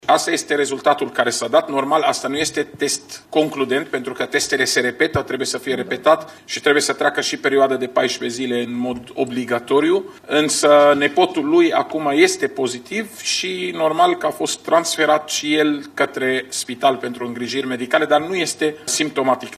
Șeful Departamentului pentru Situații de Urgențe, Raed Arafat, spune că date despre ancheta epidemiologică vor fi făcute publice ulterior.
04mar-17-Arafat-Negativ-neconcludent-și-al-5-lea-caz-asimptomatic.mp3